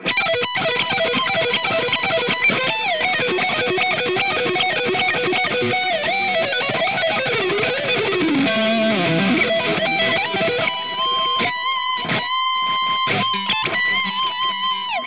The JCM900 pack includes captures ranging from clean tones to heavily distorted and everything in between plus my personal YouTube IR that I use in my demos are also included.
Lead
RAW AUDIO CLIPS ONLY, NO POST-PROCESSING EFFECTS